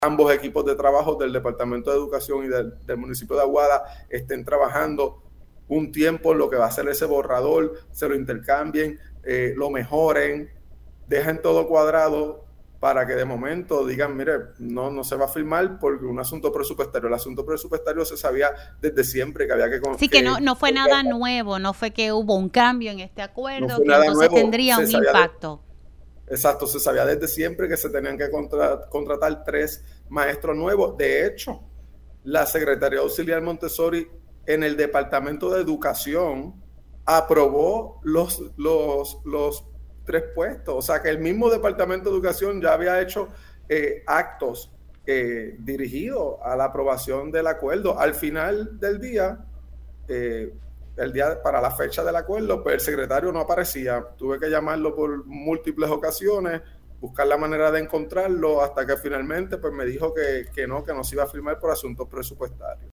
Habíamos llegado a un acuerdo de expandir ese programa en nuestro pueblo de Aguada“, explicó Cortés en Pega’os en la Mañana.